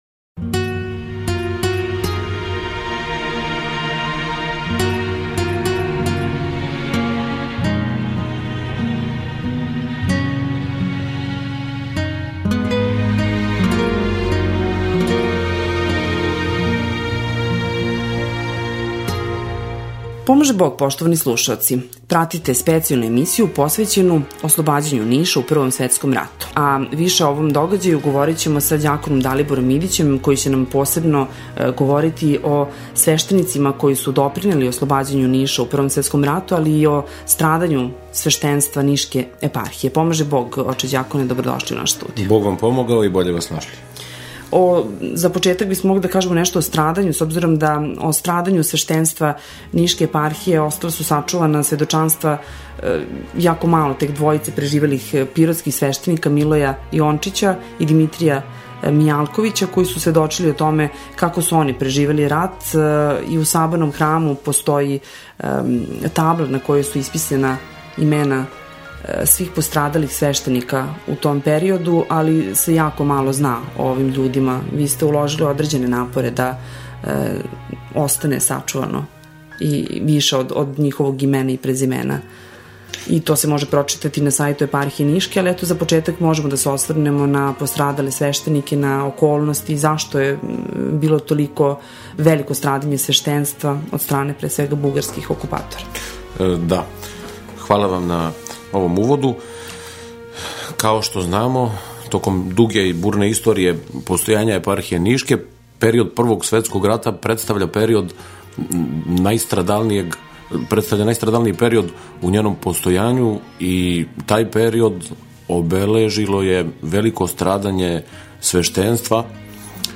Звучни запис разговора Извор: Радио Глас